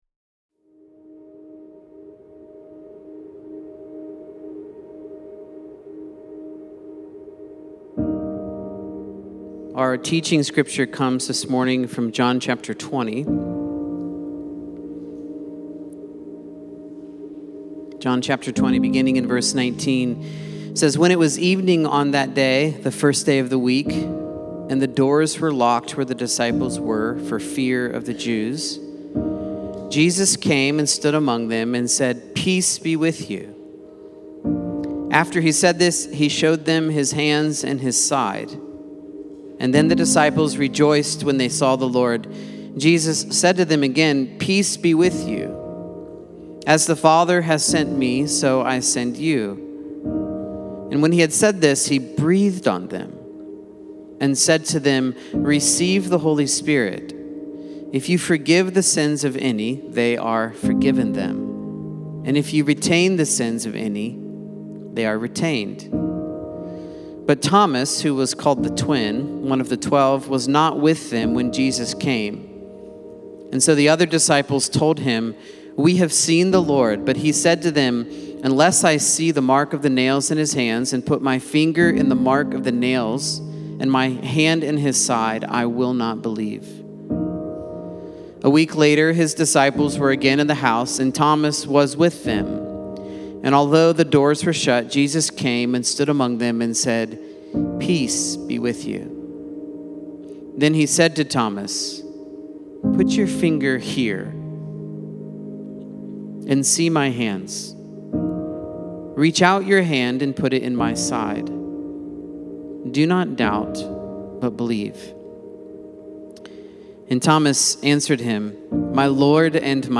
Sermons | Central Vineyard Church